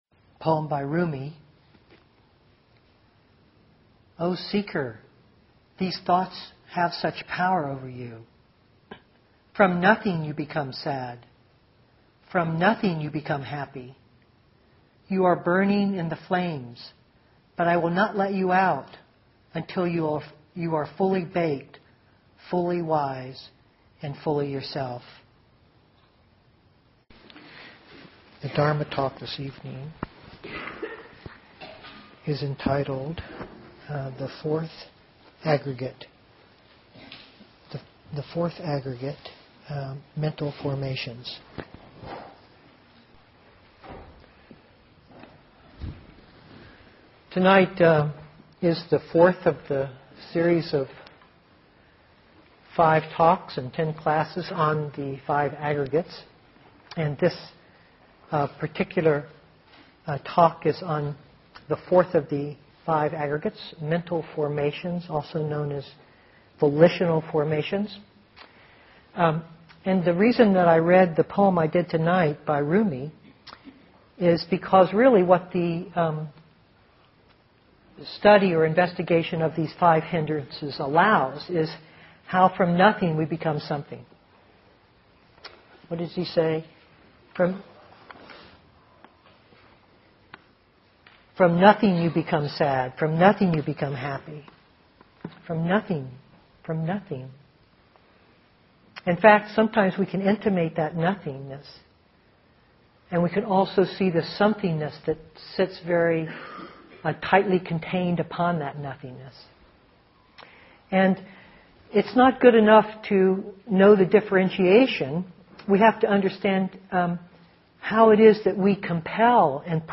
2004-10-26 Venue: Seattle Insight Meditation Center Series